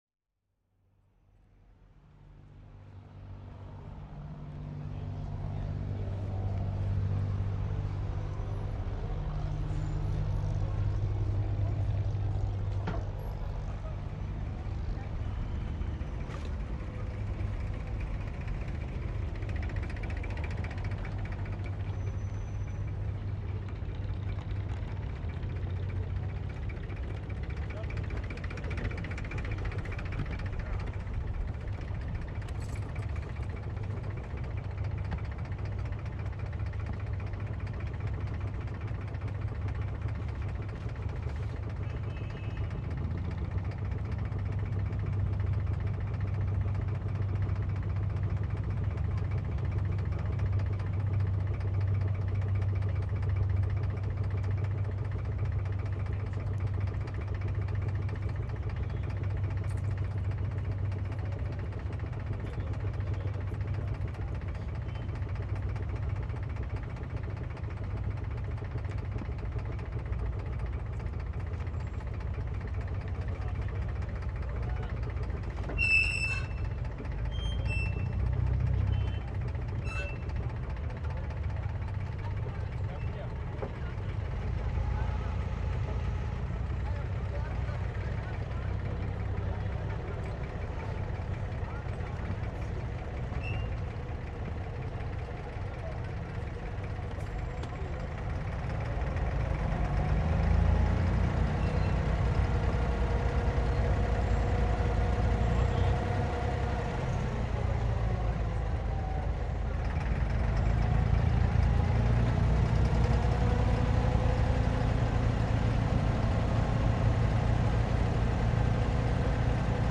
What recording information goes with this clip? field recording of a water taxi trip in Dubai.